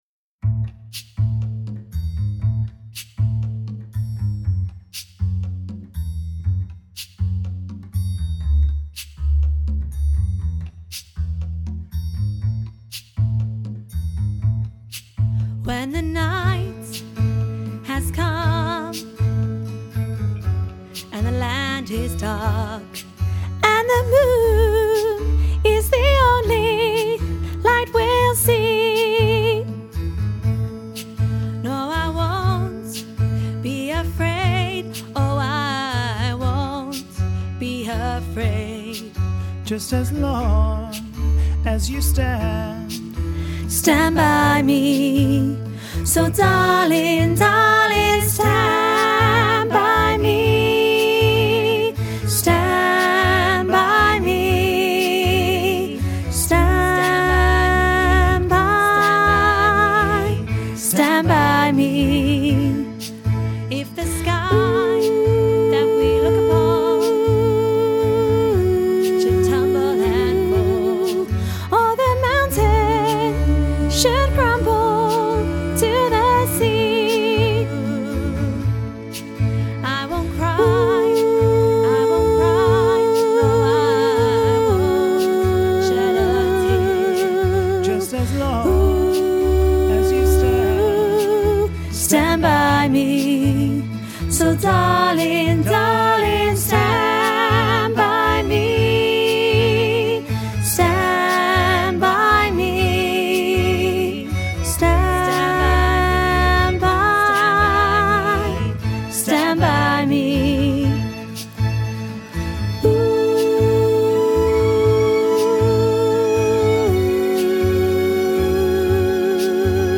stand-by-me-soprano-half-mix.mp3